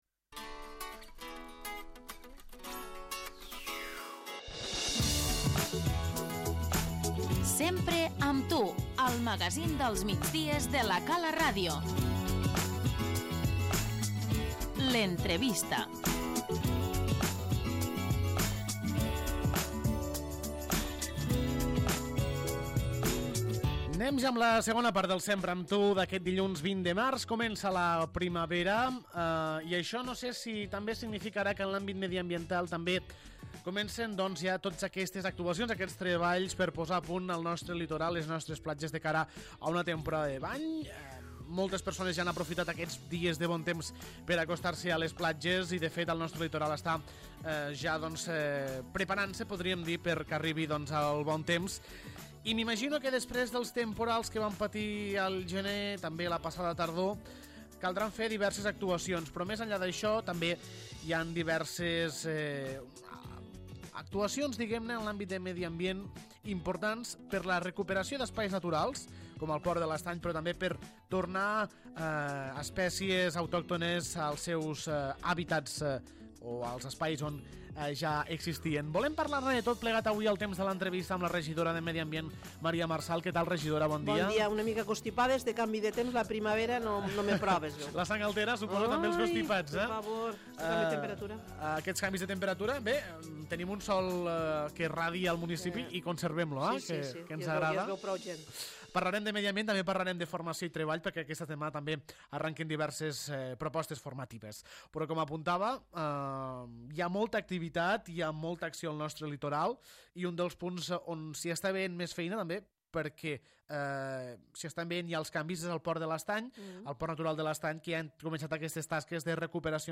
L'entrevista - Maria Marsal, regidora de Medi Ambient i de Formació i Treball
La població està immersa en diverses actuacions al seu litoral, tant en l'àmbit de recuperació d'espais naturals com en la reparació dels danys dels temporals marítims. Per explicar-nos com avancen les obres ens ha visitat la regidora de Medi Ambient, Maria Marsal, qui també gestiona les carteres de Formació i Treball, i amb qui hem parlat de l'oferta formativa que s'estrena aquesta setmana.